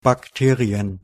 Ääntäminen
Vaihtoehtoiset kirjoitusmuodot (amerikanenglanti) bacterium Ääntäminen US US : IPA : /bækˈtɪɹ.i.ə/ UK : IPA : /bækˈtɪəɹ.ɪə/ Haettu sana löytyi näillä lähdekielillä: englanti Käännös Ääninäyte Substantiivit 1.